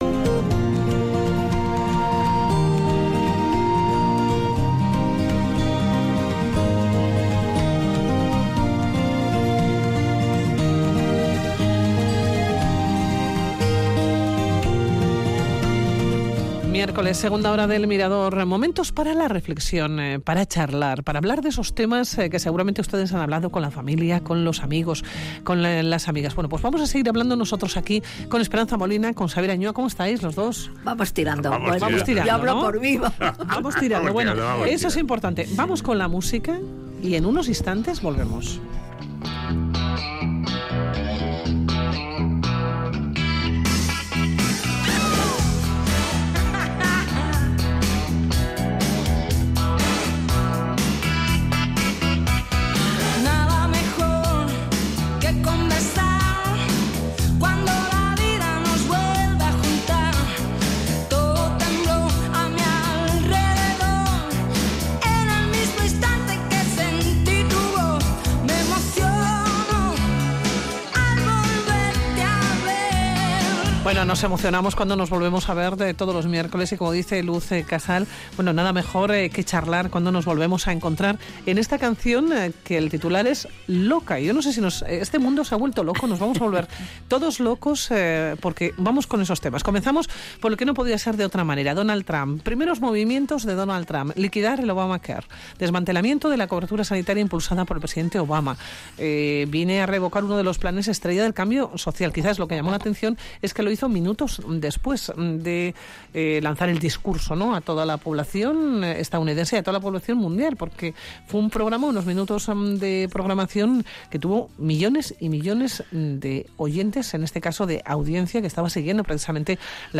Audio: Tertulia de sabios.